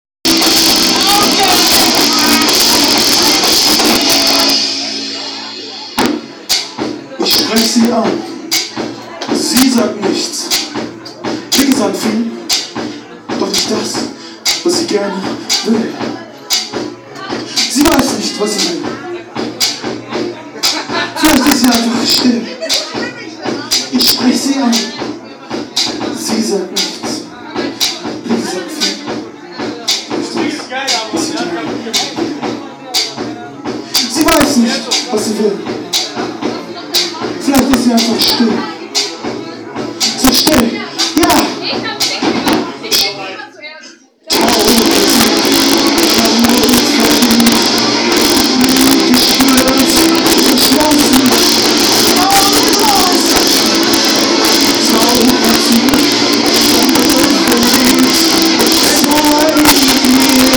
Tiefe Bässe, harte Rhythmen, trancereiche Synthie Sounds, energetische Gitarrenriffs im Zusammenspiel mit deutschen Texten, die nach dem aktuellen Empfinden greifen wollen, sind vereint in Blütenstaub.
Gesang, Gitarre
Schlagzeug
Keyboard
bringt moderne, elektrische Elemente mit klassischer Rockmusik zusammen.
Blütenstaub, Titel: Perlmutt, Hörprobe vom Live-Auftritt beim Newcomer Konzert 26.9.24